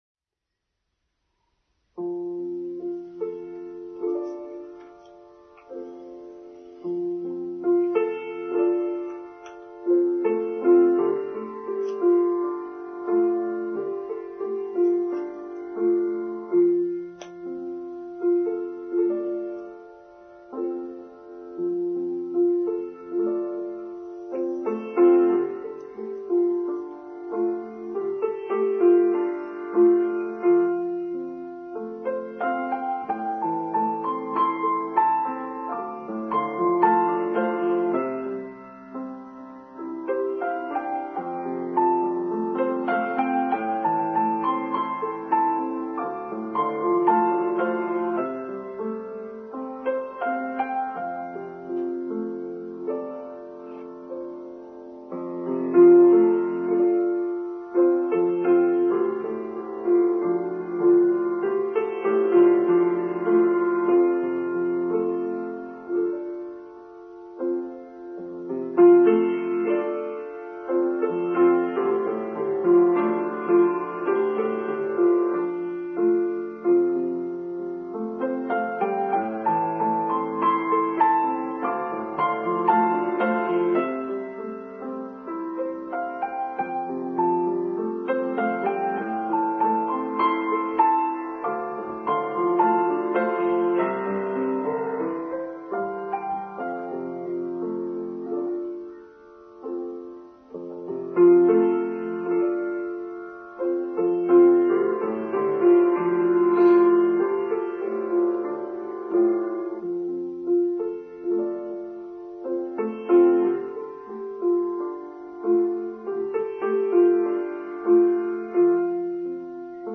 Stranger than Fiction: Online Service for Sunday 27th February 2022